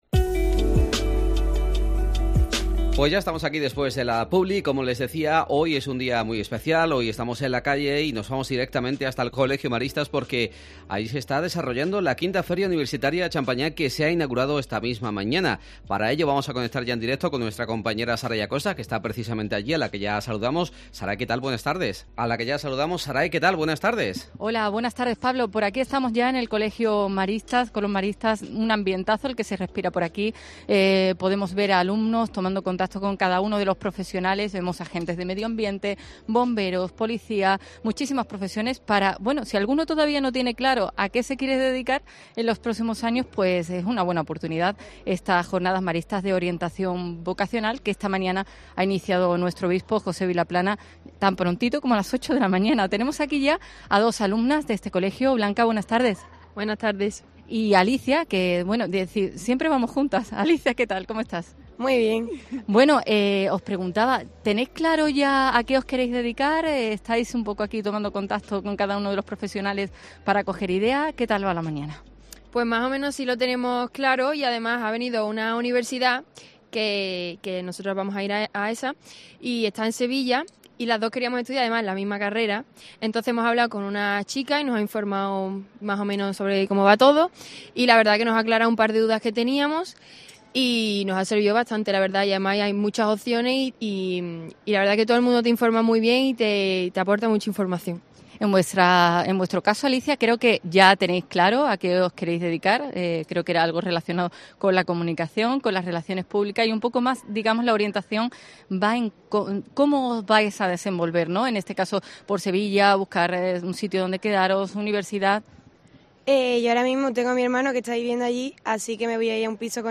AUDIO: Programa especial realizado en directo desde el Colegio Maristas con motivo de la V Feria Universitaria Champagnat.